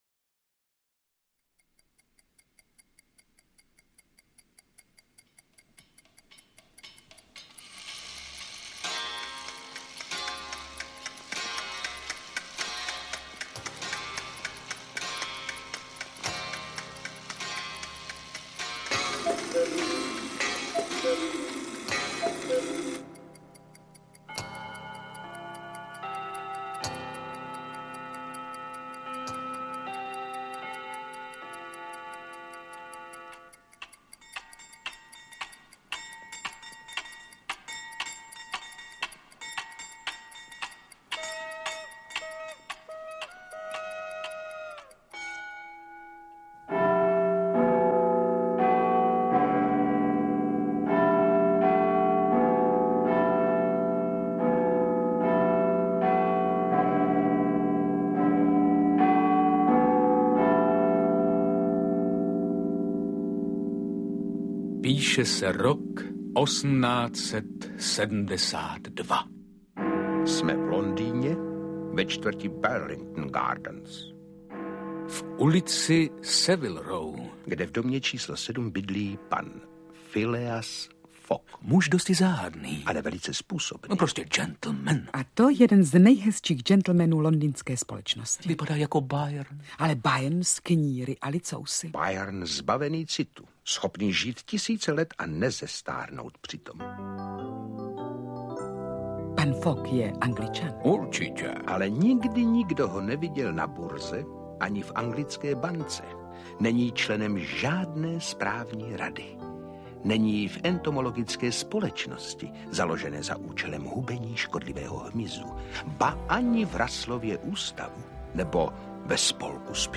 Cesta kolem světa za 80 dní, Dvacet tisíc mil pod mořem a Dva roky prázdnin - Jules Verne - Audiokniha
• Čte: Petr Nárožný, Svatopluk Beneš, Viktor…